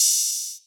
DDW Open Hat 2.wav